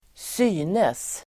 Uttal: [²s'y:nes]